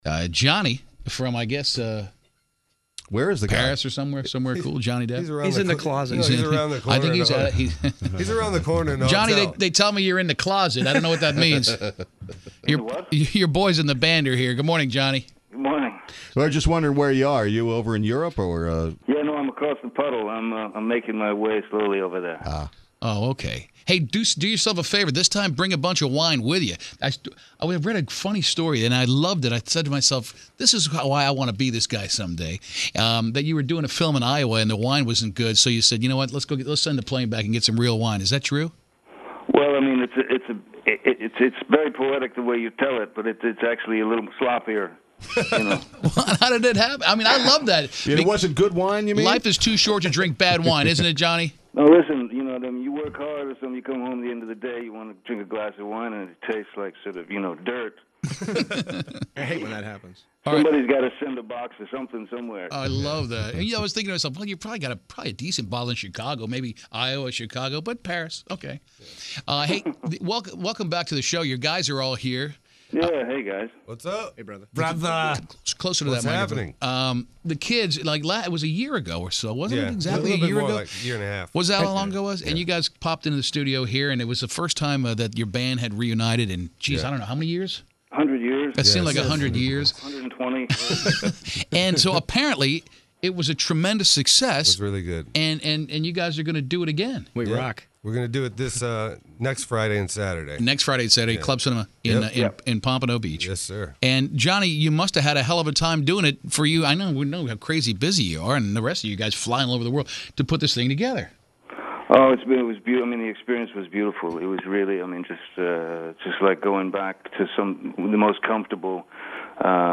Public Enemies Radio Interview